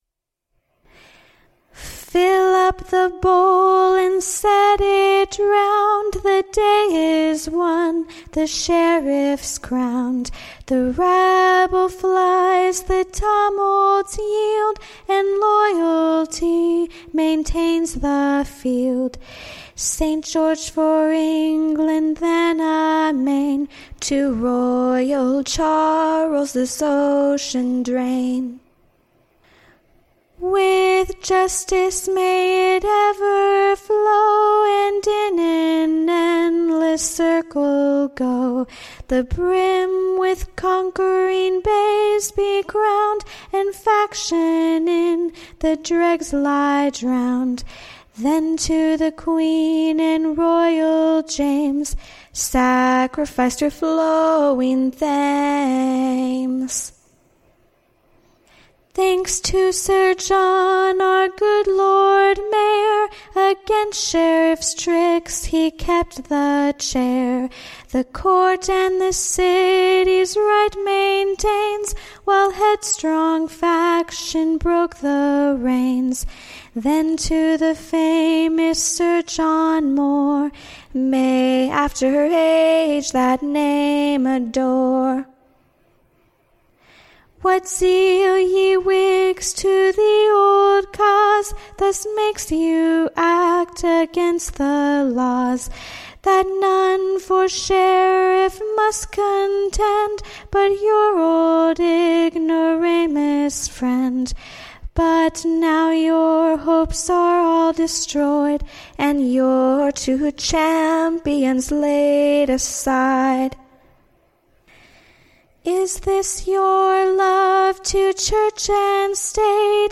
Recording Information Ballad Title Loyalty Triumphant, / On the Confirmation of Mr. North and Mr. / Rich, Sheriffs of London and Middlesex.